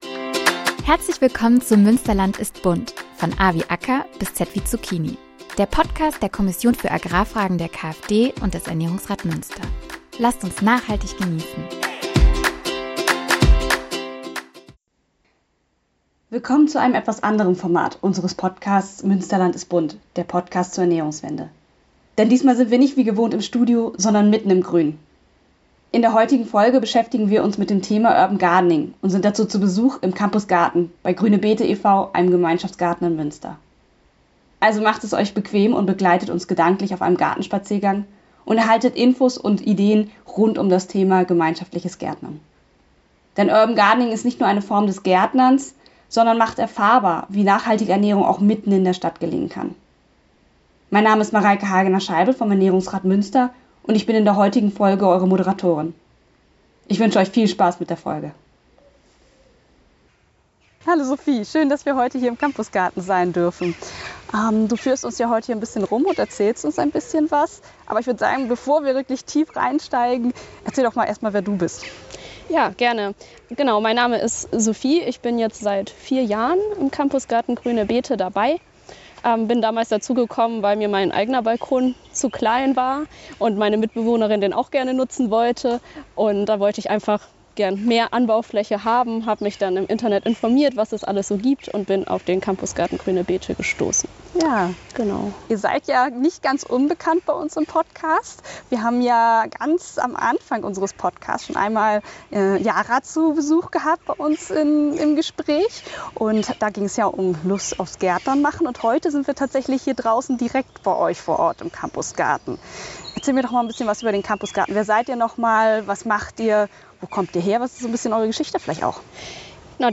In dieser Folge nehmen wir euch mit in den Campusgarten von GrüneBeete e.V. in Münster – eine grüne Oase mitten in der Stadt.